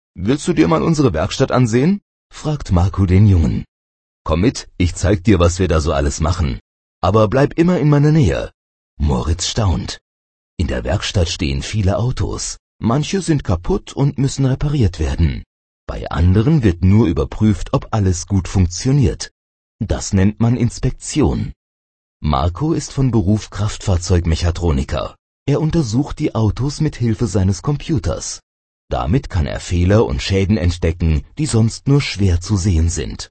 Das Hörbuch "Unsere Autowerkstatt" für unsere kleinen Kunden
Hörbuch Seite 3